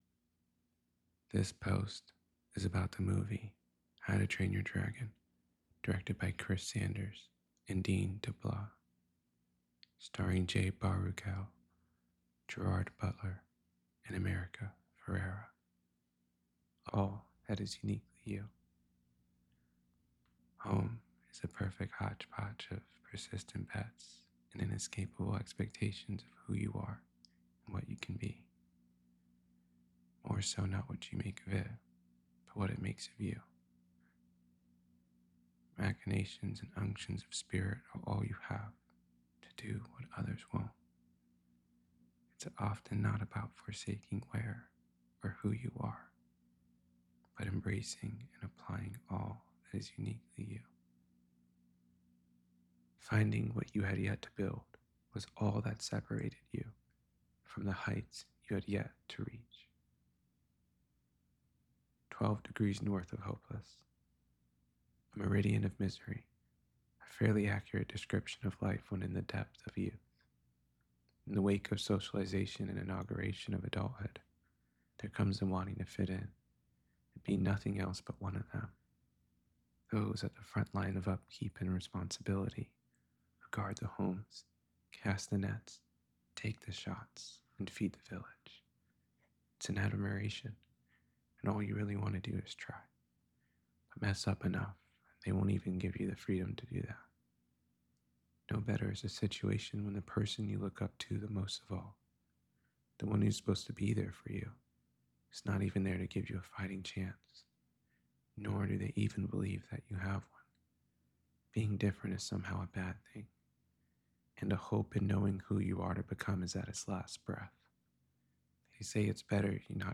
how-to-train-your-dragon-to-know-a-story-reading.mp3